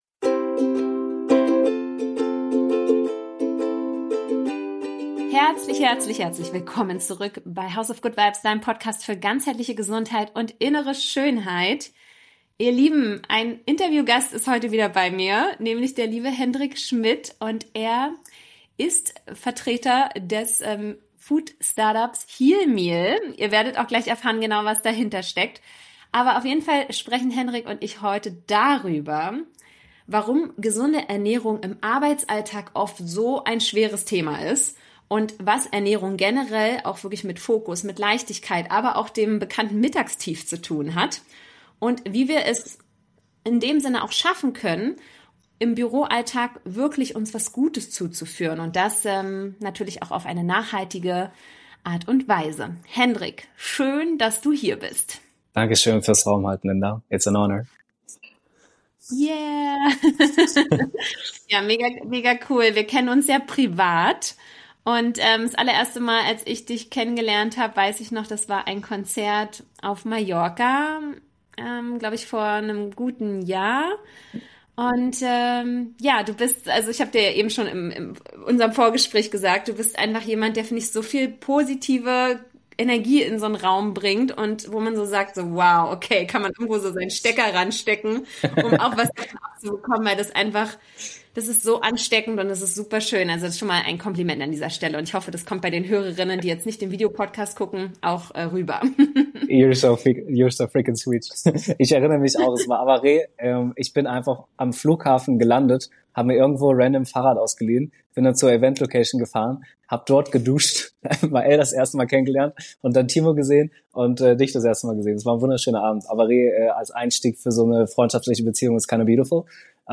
Wir sprechen darüber, inwiefern die mediterrane Ernährung für Energie und Fokus eine große Rolle spielt und wie moderne Lunch-Konzepte Office Health neu denken. Ein ehrliches, authentisches Interview mit vielen Impulsen für ein gesünderes und glücklicheres Leben.